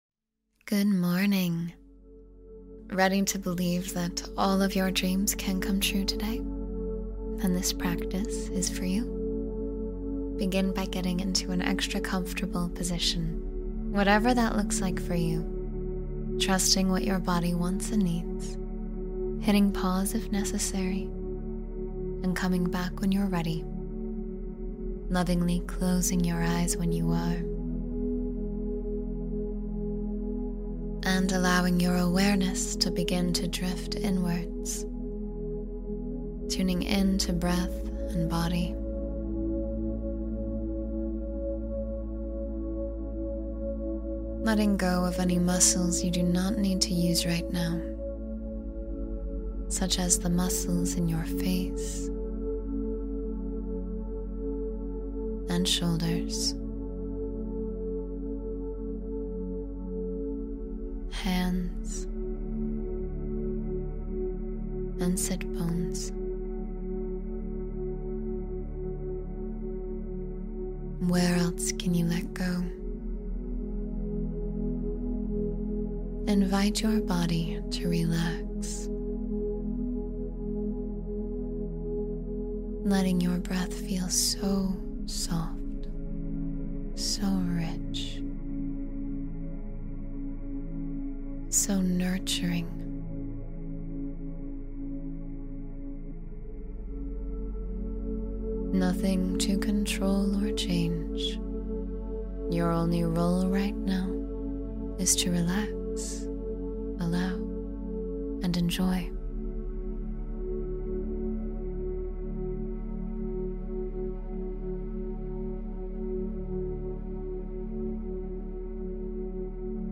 Manifest Morning Miracles in 10 Minutes — Guided Meditation for a Magical Start